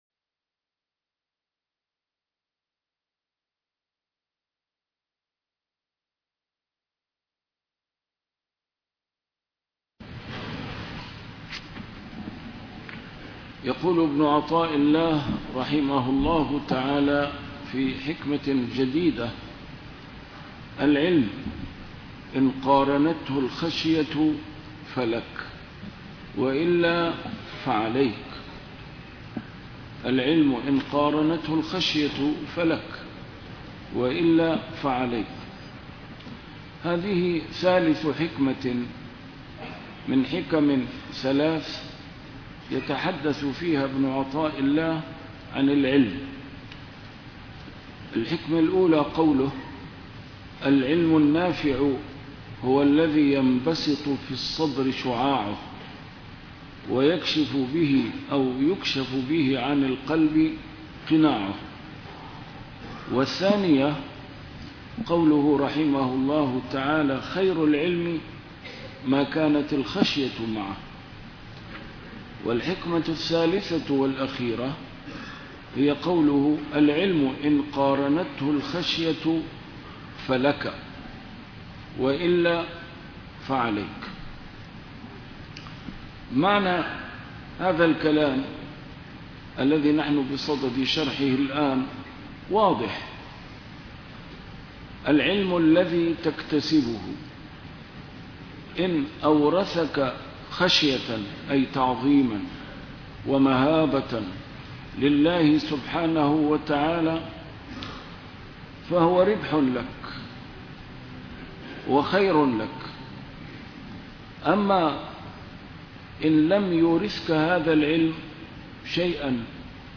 A MARTYR SCHOLAR: IMAM MUHAMMAD SAEED RAMADAN AL-BOUTI - الدروس العلمية - شرح الحكم العطائية - الدرس رقم 255 شرح الحكمة رقم 232